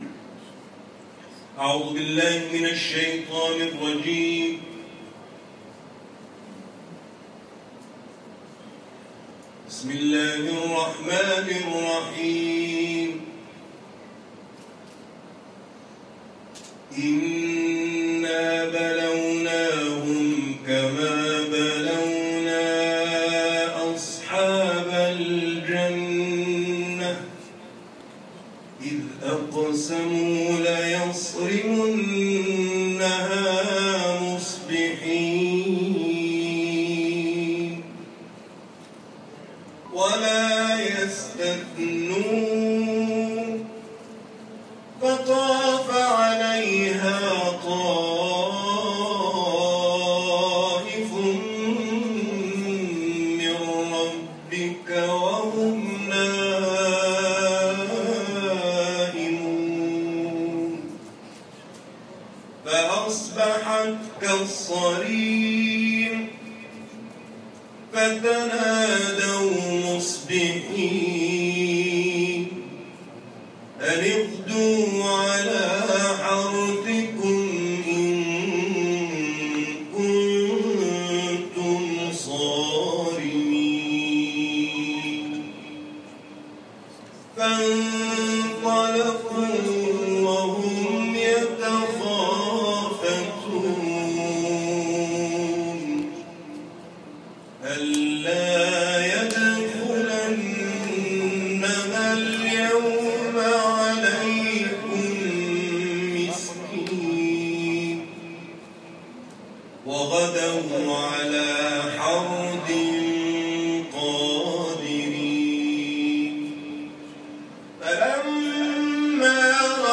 سوره قلم ، تلاوت قرآن